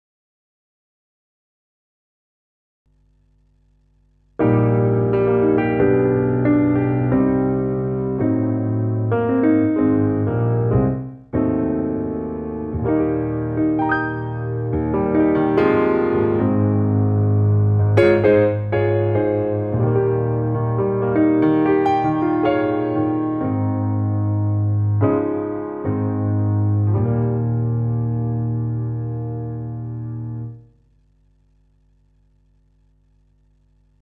Any progress ? I tried my Linux based tube notch filter ant-spoilers (3) I tried on CDs, on the latest piano patch (with tube and averaging effects built in) I made, so now it starts to sound as lively and warm I start liking to play it:
I'll have to put these effects, which make the spectrum much more believable, into the sound, so that will be some work.
This type of sound is so much more like a "living" piano that I feel no attraction almost to all those sampled things.